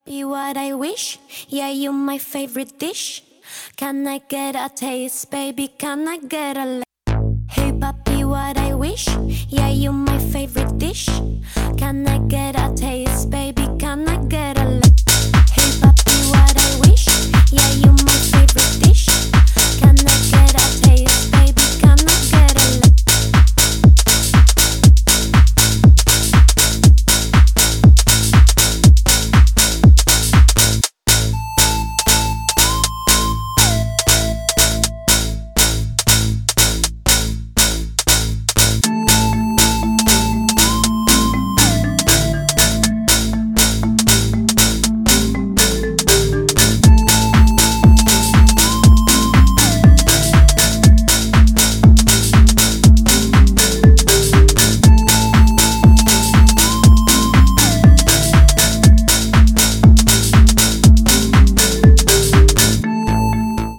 • Качество: 128, Stereo
deep house
мелодичные
progressive house
клубная музыка